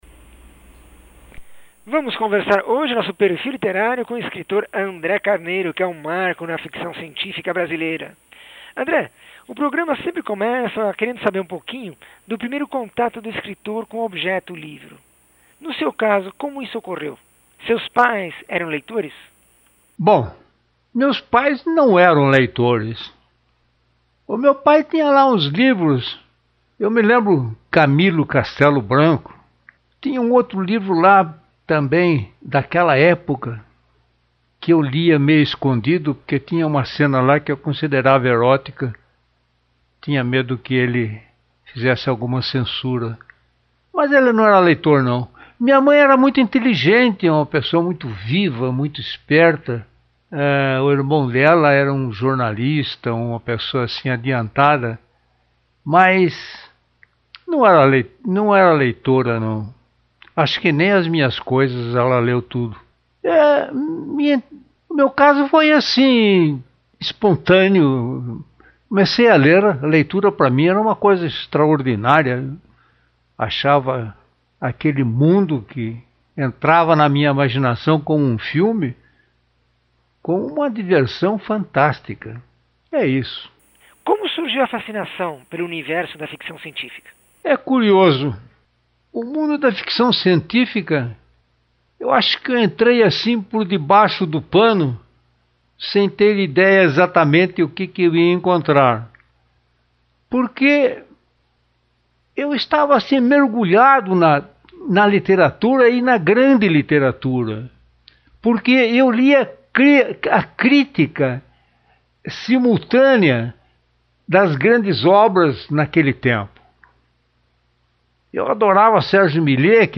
Entrevista com o escritor